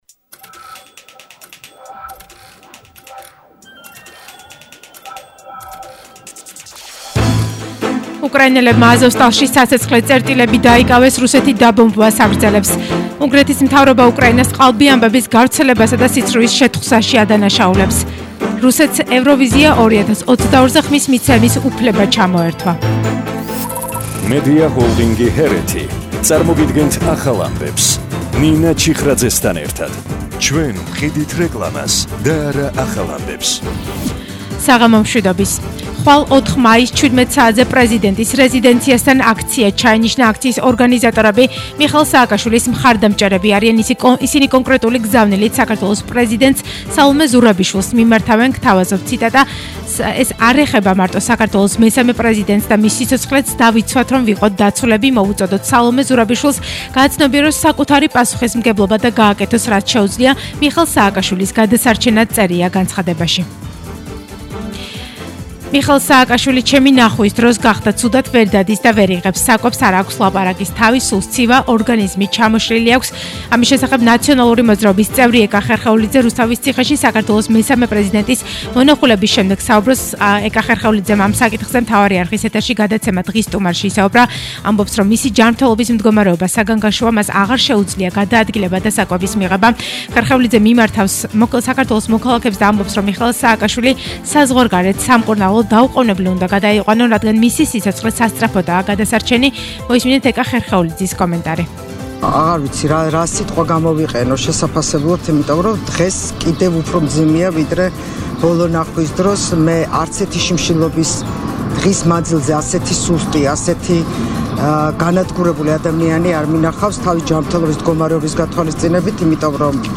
ახალი ამბები 20:00 საათზე – 03/05/22